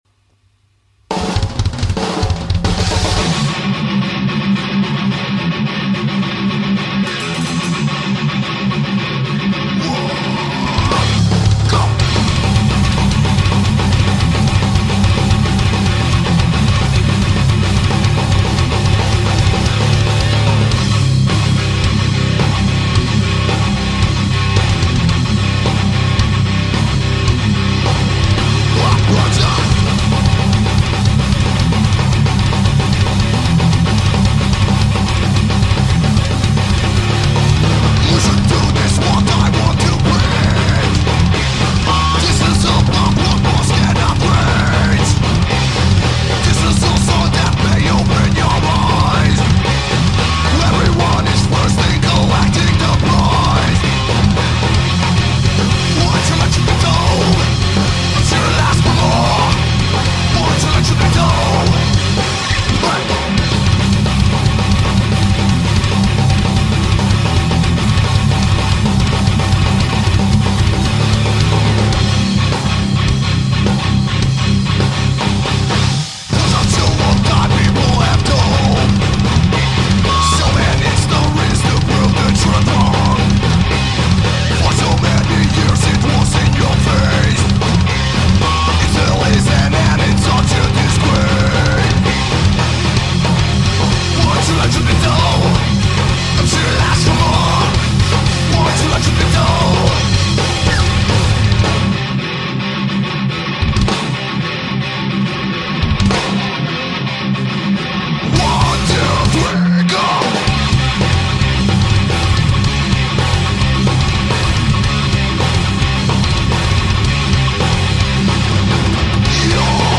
GITARA
bubnjevy